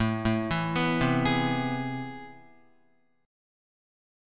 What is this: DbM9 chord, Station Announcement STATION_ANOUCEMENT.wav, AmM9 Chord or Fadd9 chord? AmM9 Chord